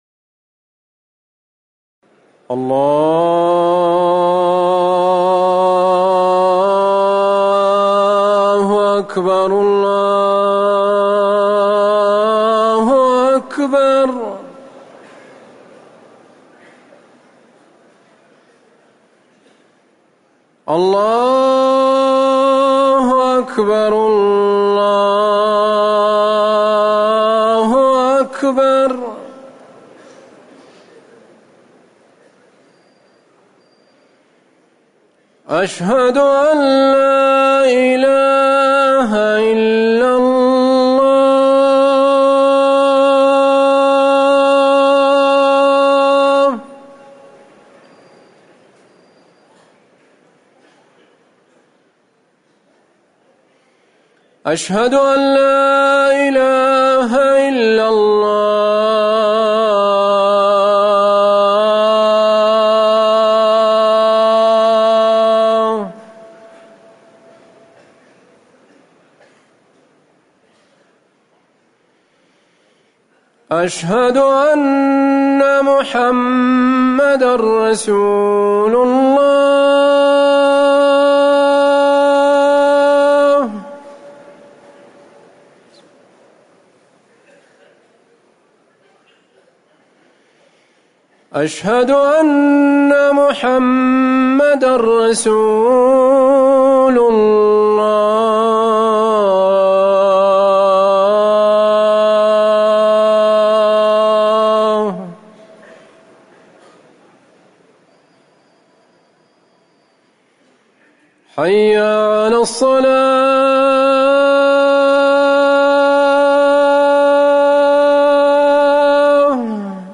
أذان العشاء - الموقع الرسمي لرئاسة الشؤون الدينية بالمسجد النبوي والمسجد الحرام
تاريخ النشر ٣ محرم ١٤٤١ هـ المكان: المسجد النبوي الشيخ